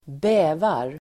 Ladda ner uttalet
Uttal: [²b'ä:var]